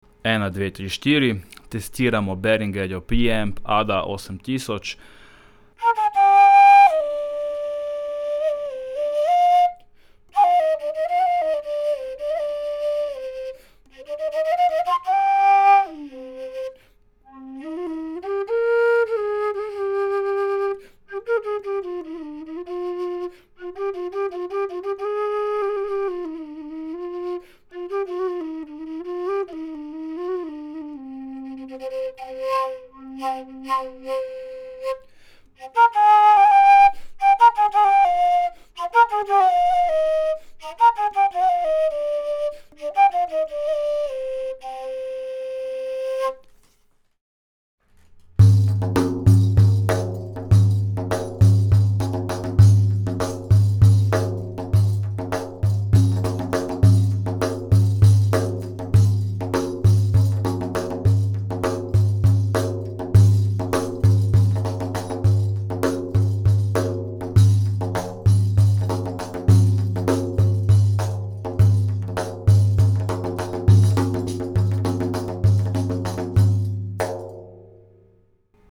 test 1. je E-MU
Mikrofon je shure SM81, posnet pa je govor, makedonski duduk (pihalo) in
obrocni boben!